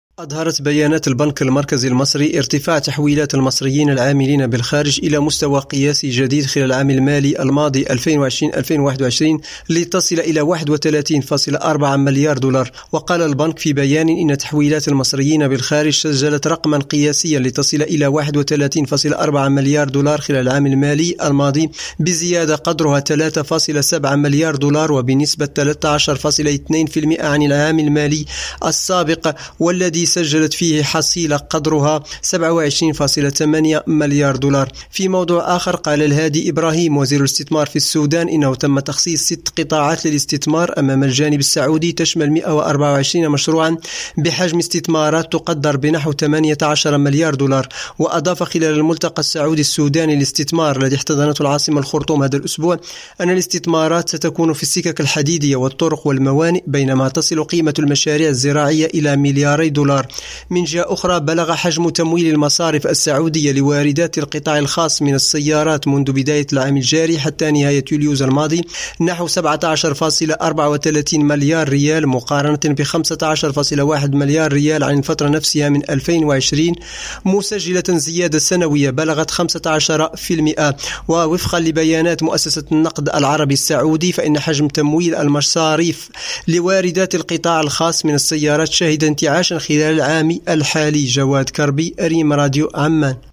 Bulletins d'information